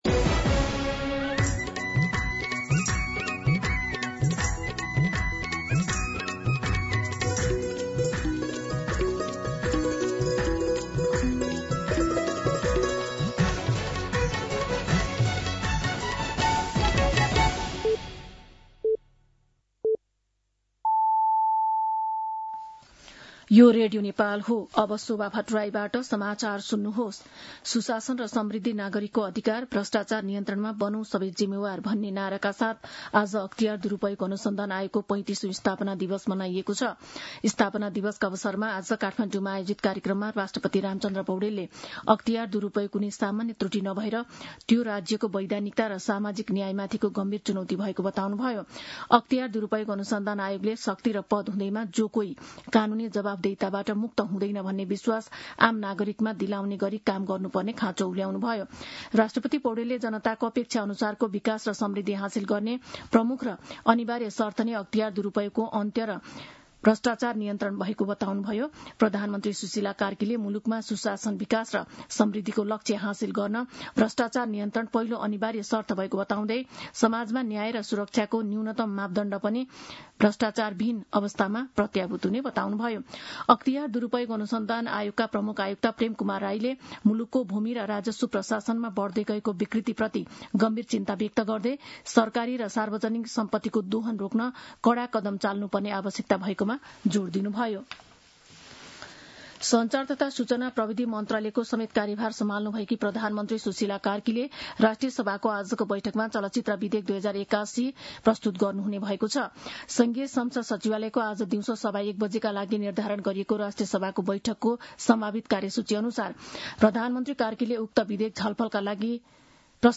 मध्यान्ह १२ बजेको नेपाली समाचार : २८ माघ , २०८२